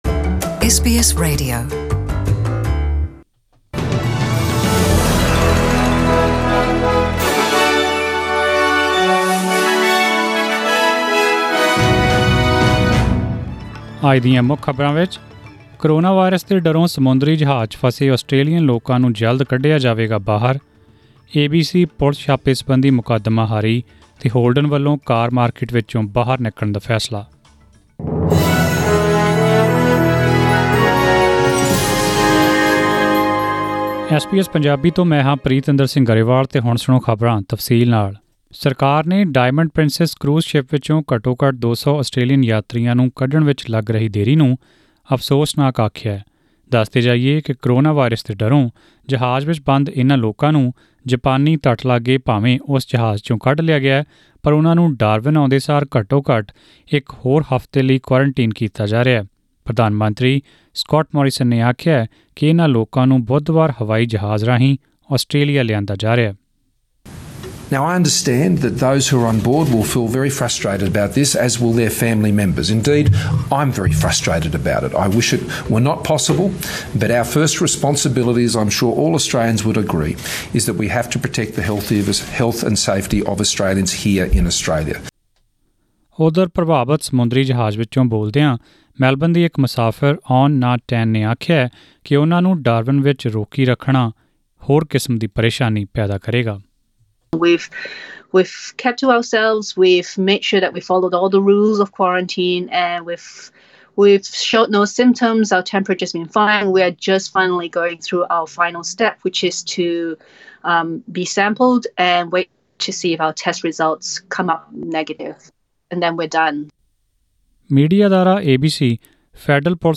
Australian News in Punjabi: 17 February 2020